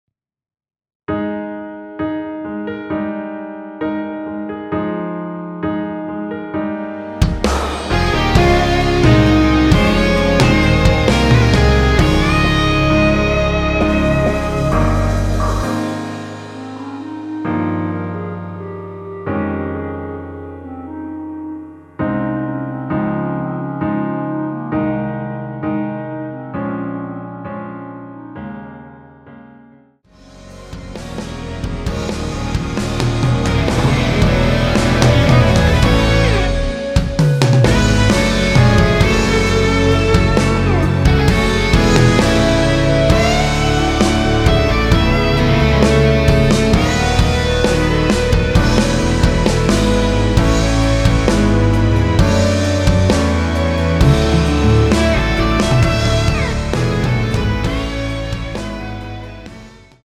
원키에서(-5)내린 멜로디 포함된 MR입니다.
Ab
앞부분30초, 뒷부분30초씩 편집해서 올려 드리고 있습니다.